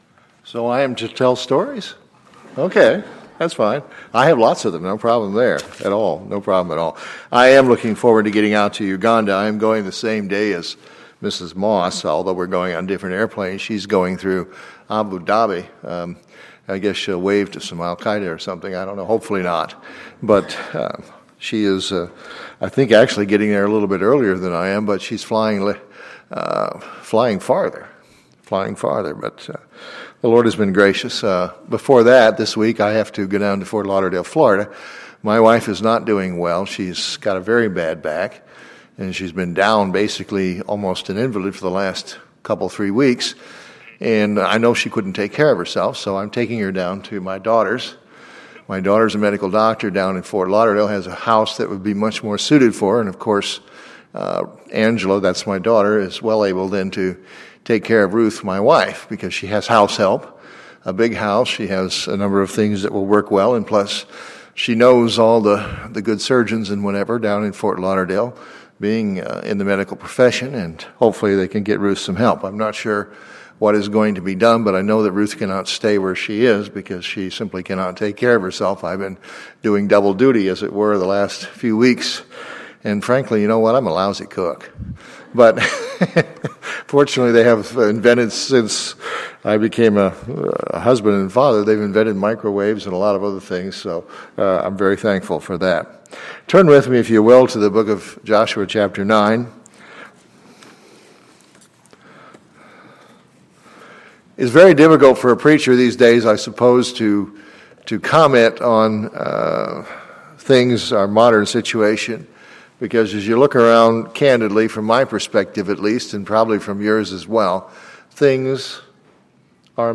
Sunday, July 20, 2014 – Morning Service